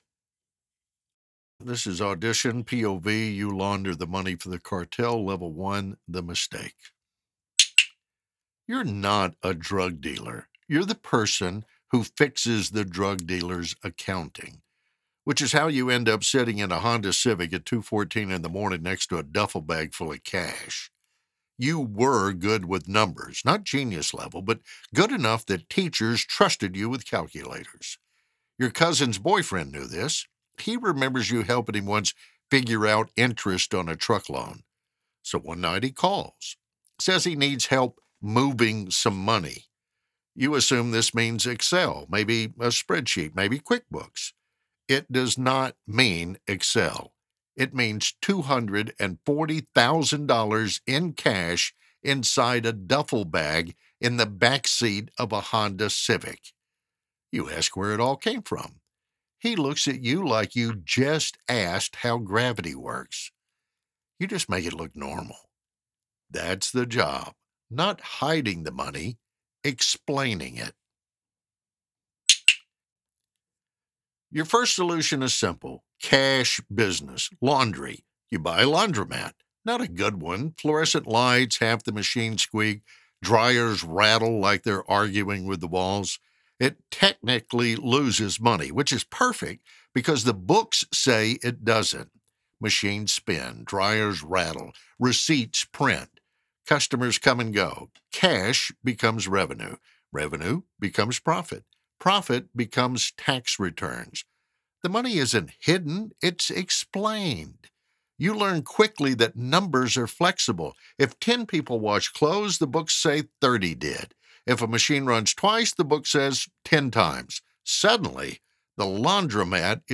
Professional Voice Over Artist
Narration for a YouTube Video Satire on Money Laundering
English Neutral, English - Southern US
Senior